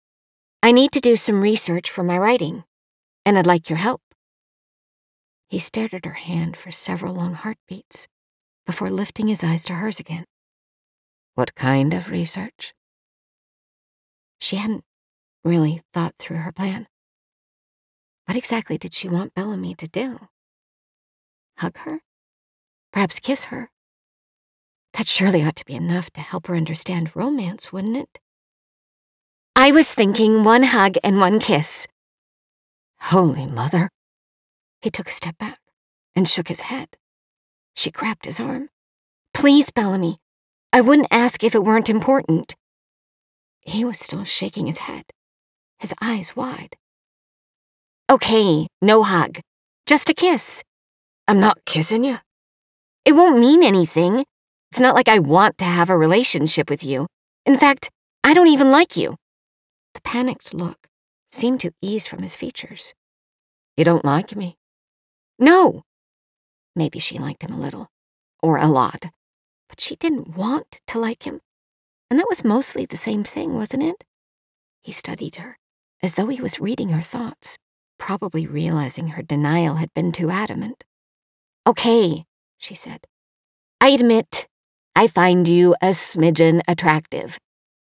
AUDIOBOOK  SAMPLES
Historical Romance      |    Third Person    |    Irish Male / Female Dialogue